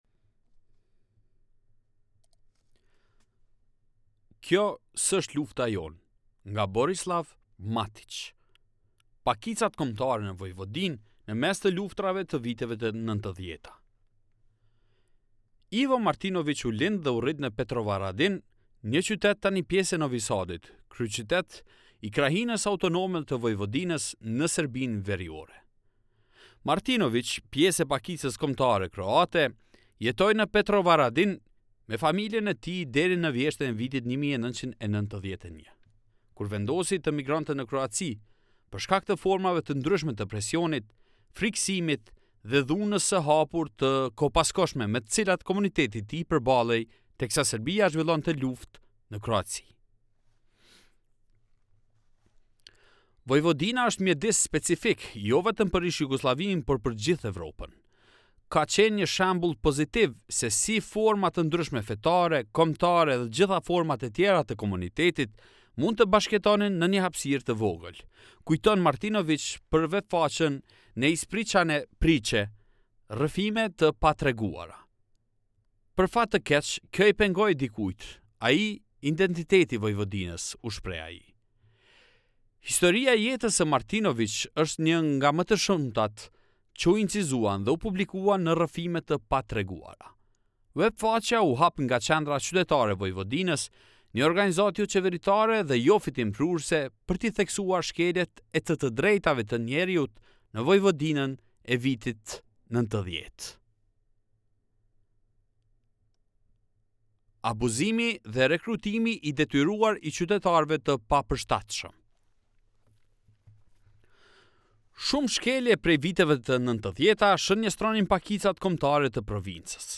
Më poshtë gjeni një version të lexuar me zë të të gjithë artikullit.